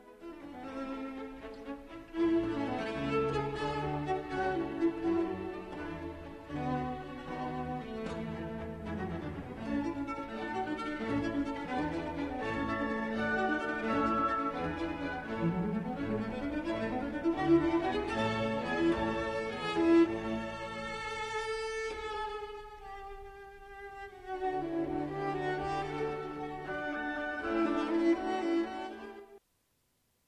بشنوید قسمتی از کنسرتو ویولونسل را ساخته هایدن با سولیستی ژاکلین دوپره